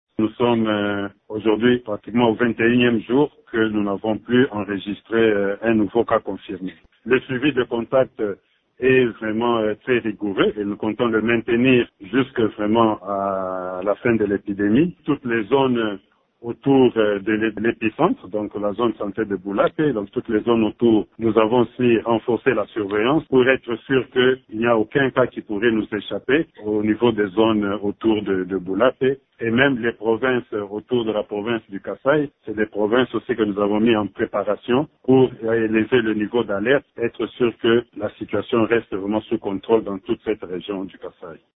Pour le directeur général de l'Institut national de santé publique, Dr Dieudonné Muamba, contacté par Radio Okapi, ce succès est dû à la surveillance épidémiologique efficace dans cette zone de santé: